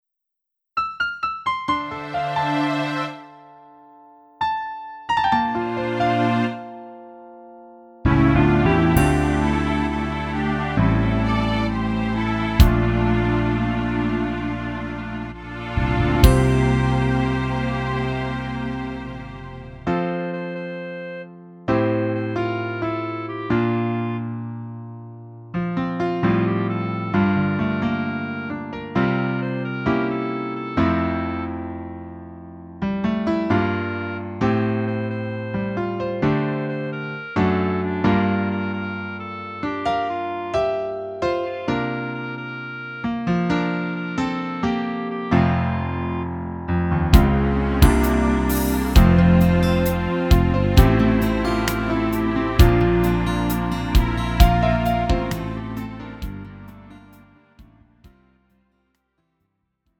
음정 원키 4:05
장르 가요 구분 Lite MR